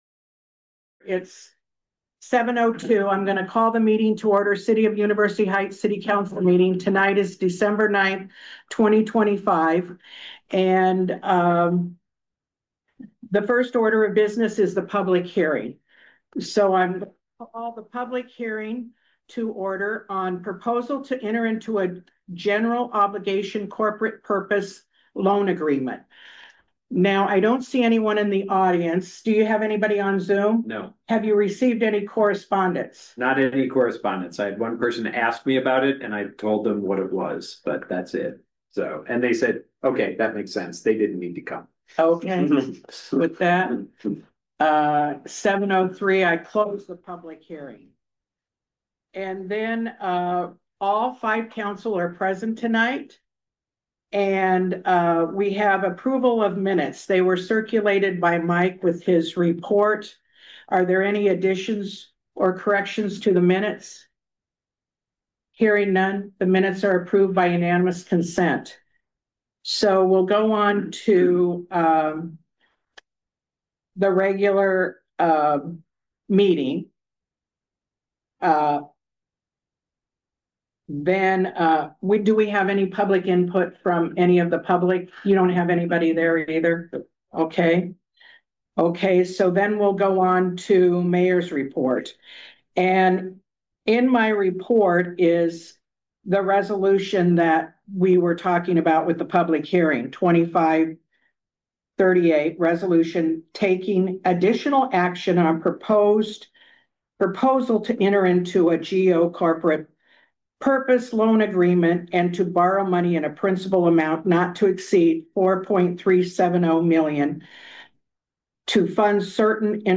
University Heights City Council Meeting of 12/09/25
The monthly meeting of the University Heights City Council.